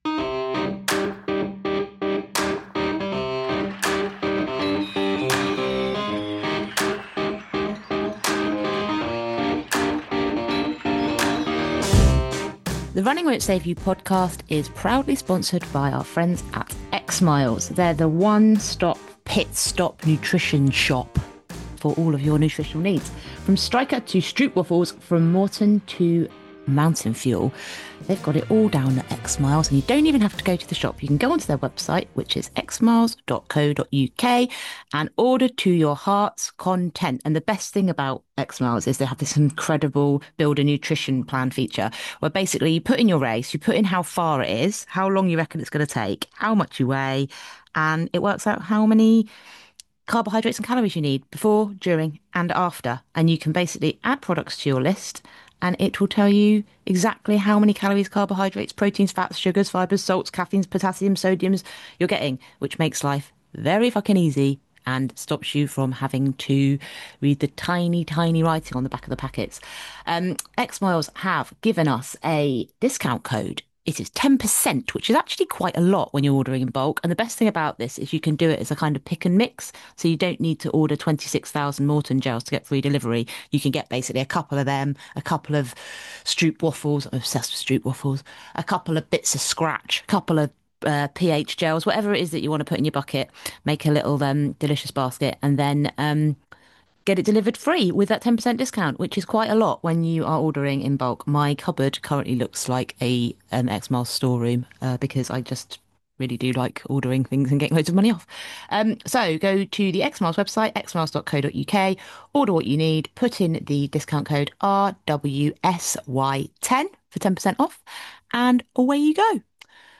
I'd say the pace of this pod is slow at best - sorry about that!